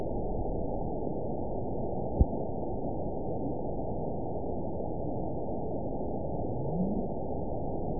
event 915393 date 11/30/22 time 20:20:29 GMT (3 years ago) score 8.70 location INACTIVE detected by nrw target species NRW annotations +NRW Spectrogram: Frequency (kHz) vs. Time (s) audio not available .wav